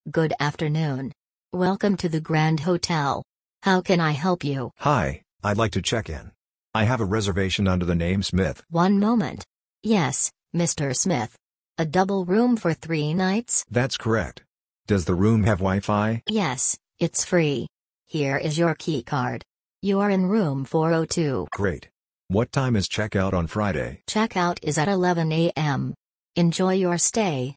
🎧 Shadowing Exercise: The Arrival
Listen to the formal register. The receptionist is very polite; the guest uses “I’d like to” instead of “I want.”